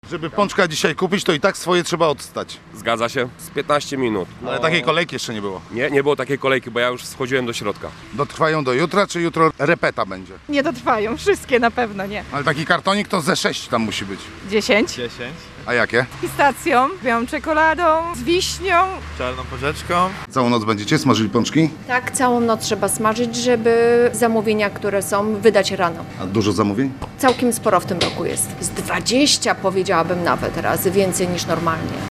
Jak do pączkowego święta szykują się pączkarnie w Gdyni i czy mieszkańcy kupują je z wyprzedzeniem, aby uniknąć kolejek? Sprawdził to nasz reporter: